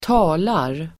Uttal: [²t'a:lar]